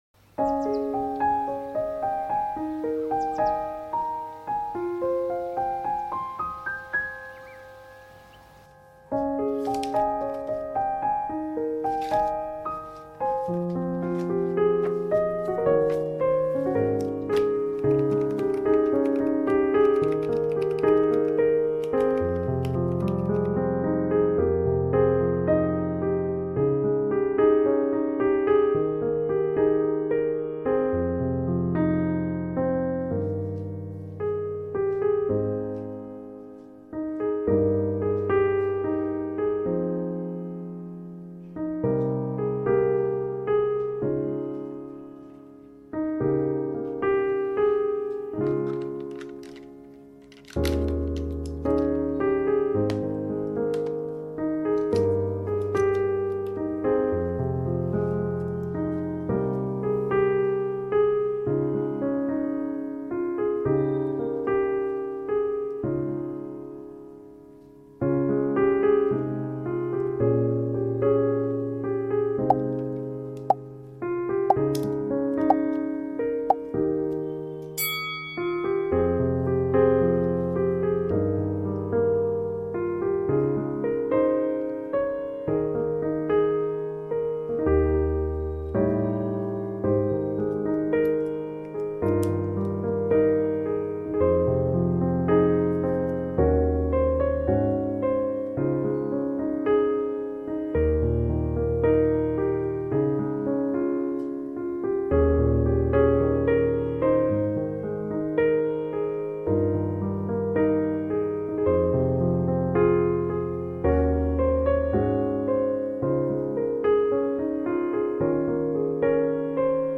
Étude 1h : Nuit Profonde, Piano & Pluie